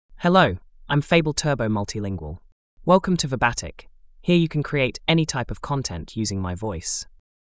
Fable Turbo MultilingualFemale English AI voice
Fable Turbo Multilingual is a female AI voice for English (United States).
Voice sample
Listen to Fable Turbo Multilingual's female English voice.
Fable Turbo Multilingual delivers clear pronunciation with authentic United States English intonation, making your content sound professionally produced.